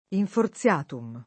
vai all'elenco alfabetico delle voci ingrandisci il carattere 100% rimpicciolisci il carattere stampa invia tramite posta elettronica codividi su Facebook Infortiatum [lat. infor ZL# tum ] tit. m. (in it.) — nome mediev. dei libri XXIV‑XXXVIII del Digesto — cfr. inforziato